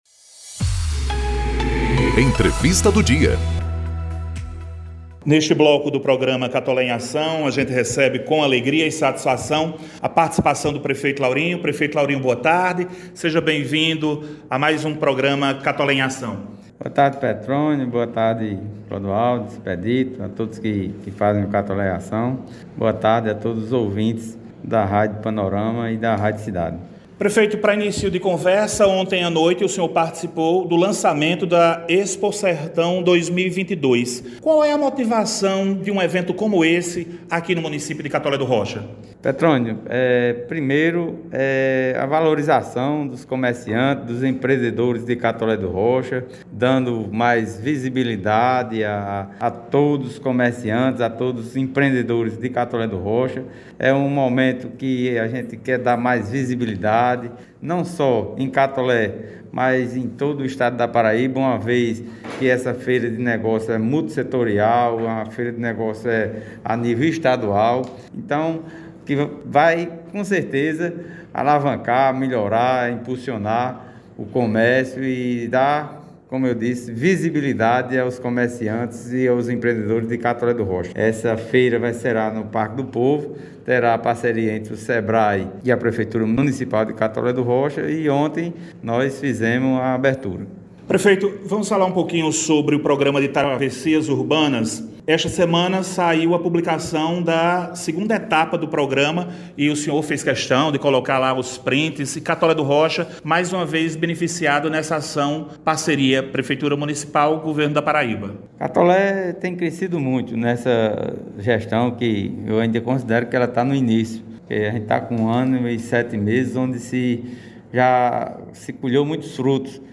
Ouça a entrevista na íntegra: Prefeito Laurinho faz balanço de ações, serviços e obras - Folha Paraibana
O Programa Institucional “Catolé em Ação” – edição n° 54 – exibiu, na sexta-feira (29/07), entrevista com o prefeito de Catolé do Rocha Laurinho Maia.